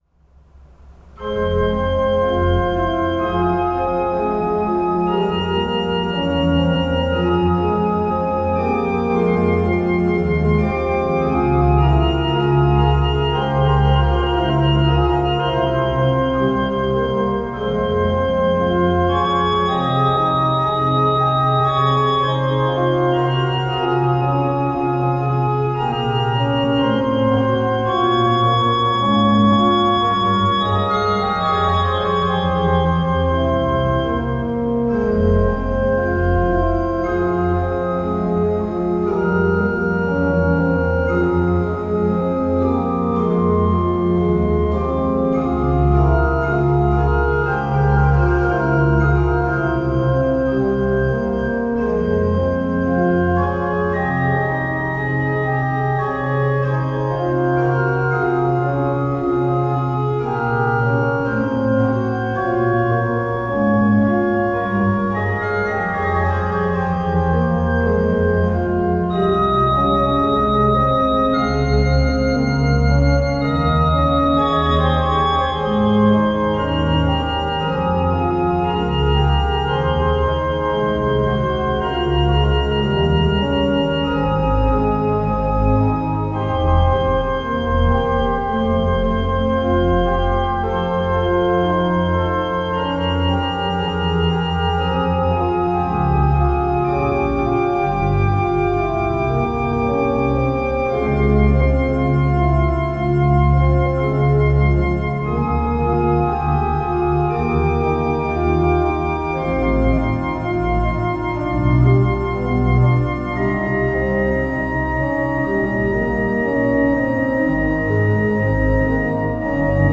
Audio/Video - Organo Basilica Cattedrale di Fossano
Registrizioni amatoriali realizzate con Zoom H5N con capsula Zoom Msh-6 e/o microfoni esterni stereo AKG SE300B
Brani periodo Barocco